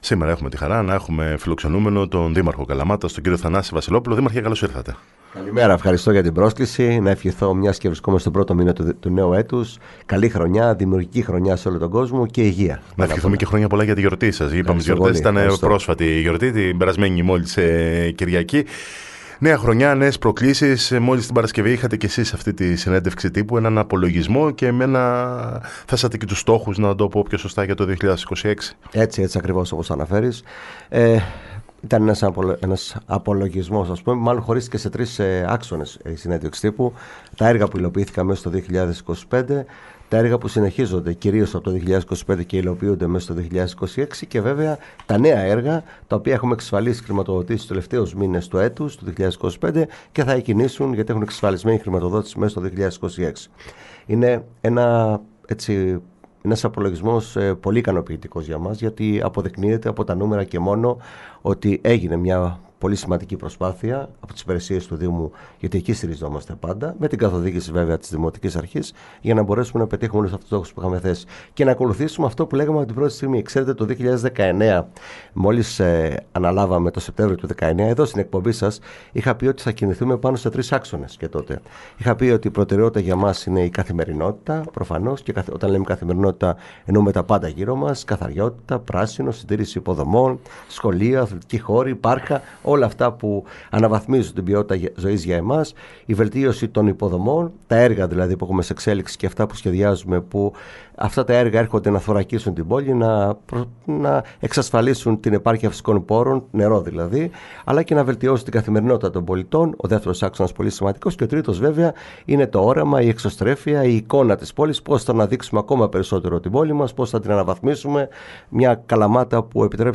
Ο Δήμαρχος Καλαμάτας, Θανάσης Βασιλόπουλος στο ραδιόφωνο της ΕΡΤ Καλαμάτας | 20.01.2026
Σε συνέντευξή του στην εκπομπή «Πρωινό στον Αέρα» της ΕΡΤ Καλαμάτας, ο δήμαρχος Καλαμάτας Θανάσης Βασιλόπουλος παρουσίασε τον απολογισμό έργων του Δήμου, τονίζοντας ότι η έμφαση δίνεται στις υποδομές που διασφαλίζουν την ασφάλεια, την ποιότητα ζωής και τη βιώσιμη ανάπτυξη της πόλης.